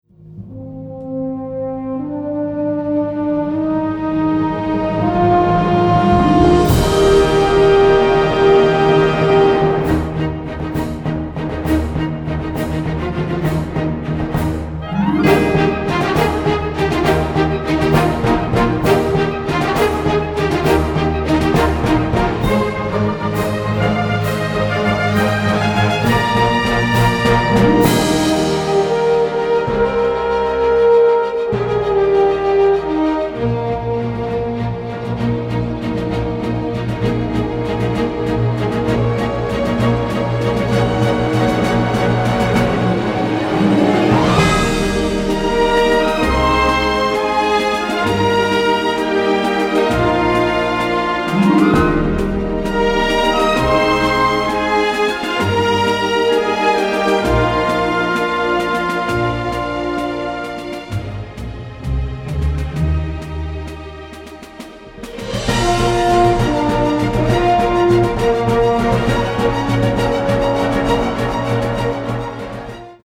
symphonic, varied, funny and emotional